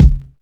• Short Bass Drum E Key 100.wav
Royality free bass drum single shot tuned to the E note. Loudest frequency: 139Hz
short-bass-drum-e-key-100-ULV.wav